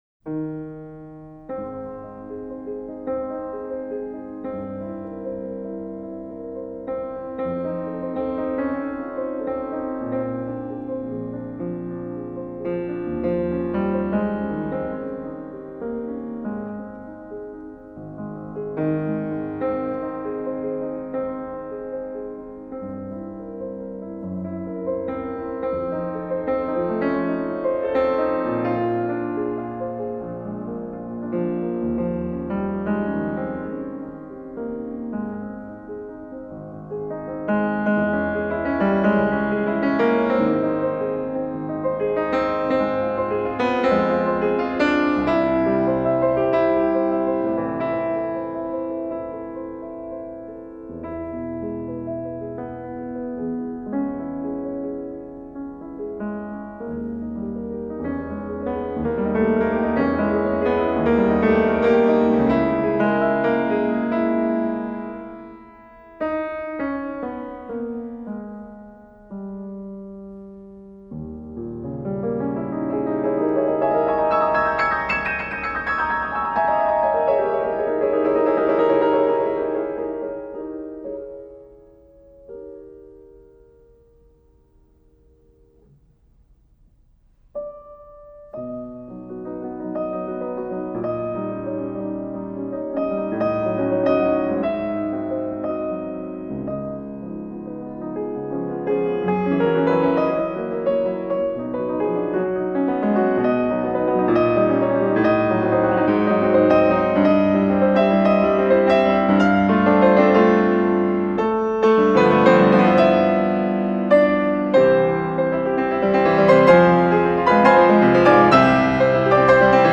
pianist
I’m enjoying the refreshing sound.
Outstanding delicacy of touch and clarity.